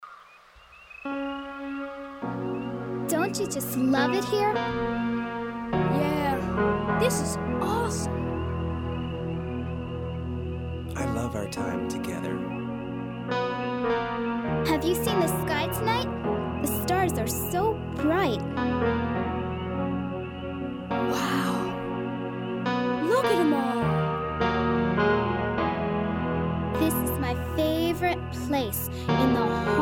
Home > Lullabies